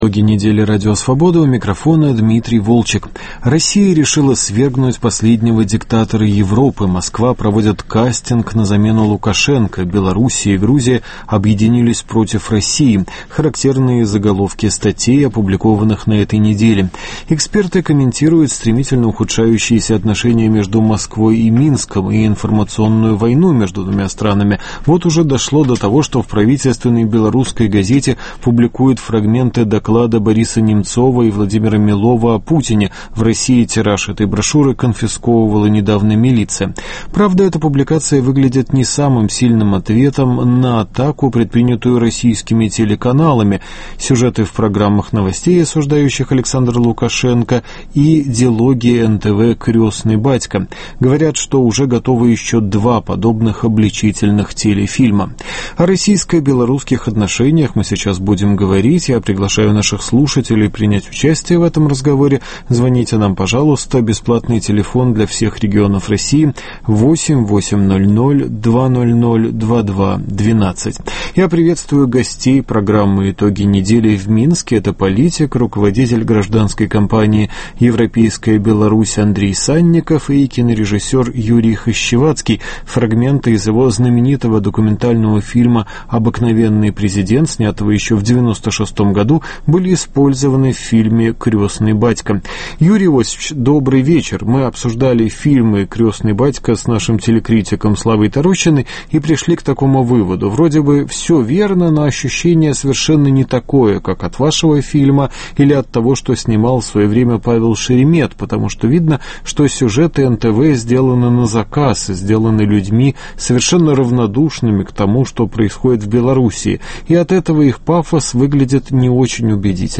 Разговор с экспертами о повороте в российско-белорусских отношениях (гости программы – А. Санников и Ю. Хащеватский), обзоры еженедельников и блогов, Гламорама и реплика Виктора Шендеровича